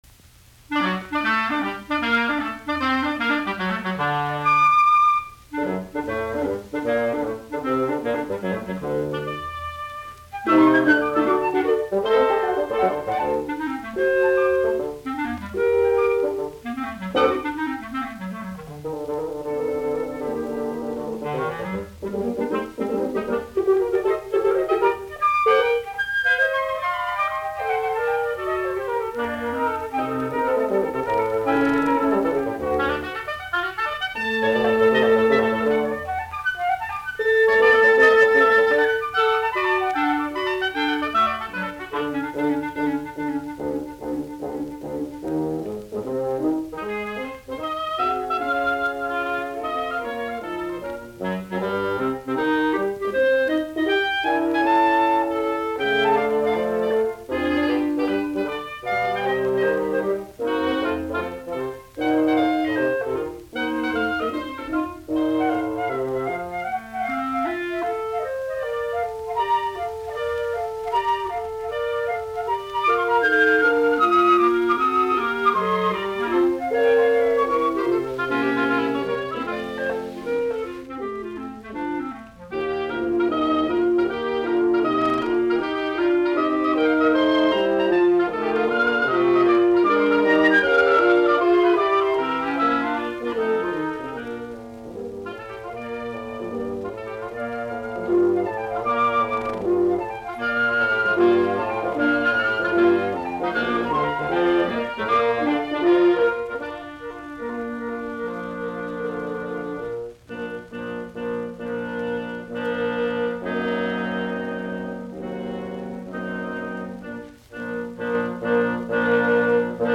Kvintetot, puhaltimet, g-molli
Soitinnus: Huilu, oboe, klarinetti, käyrätorvi, fagotti.